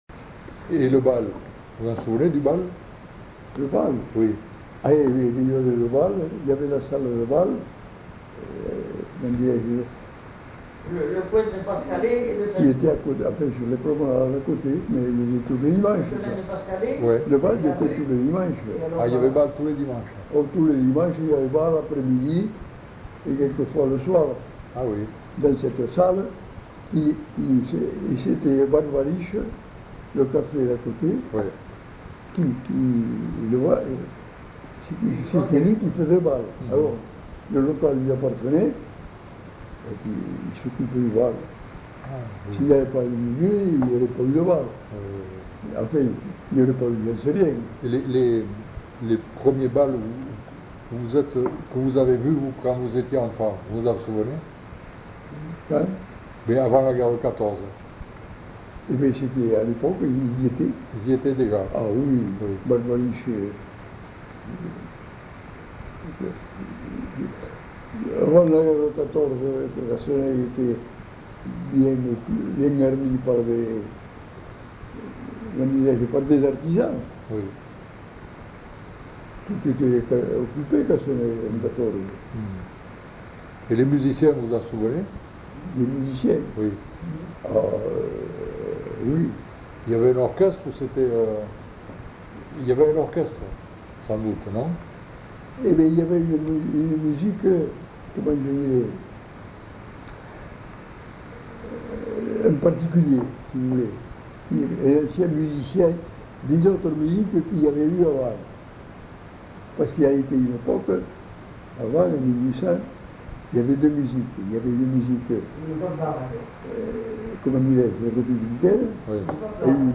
Genre : témoignage thématique
Notes consultables : Un homme non identifié intervient de temps en temps dans la discussion.